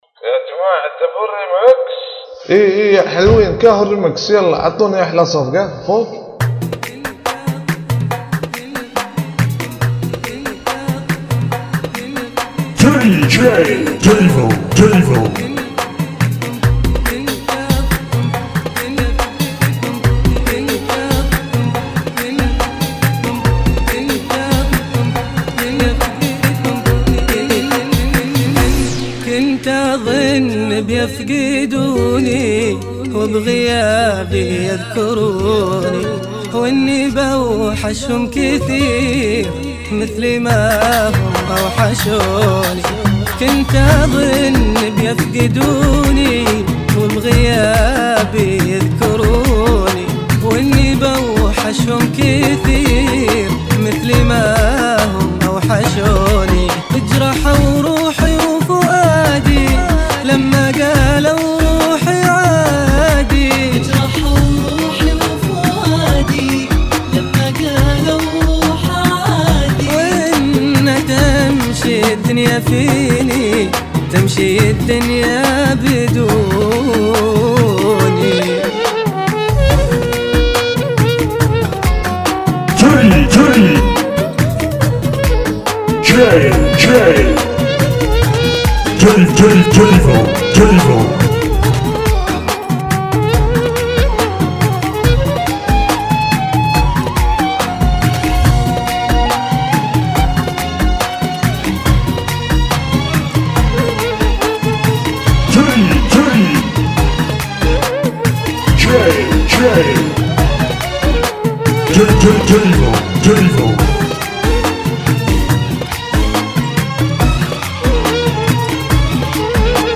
RiMx